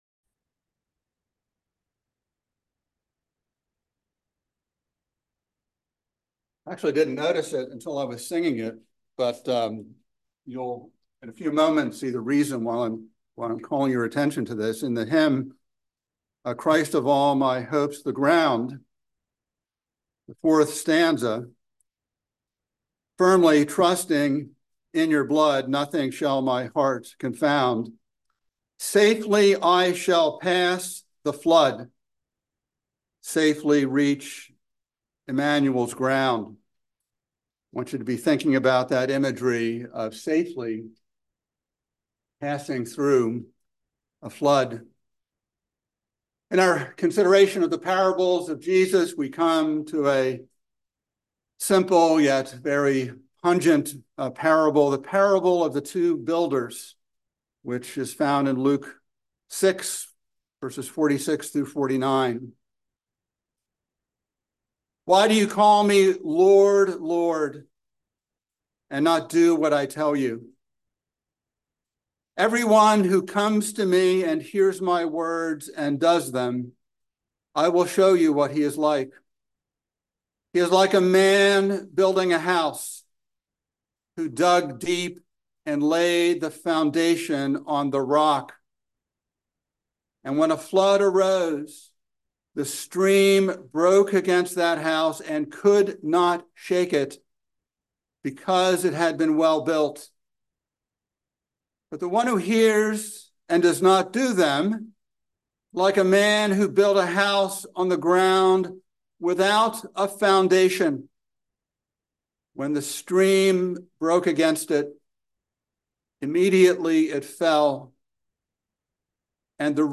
by Trinity Presbyterian Church | Feb 15, 2024 | Sermon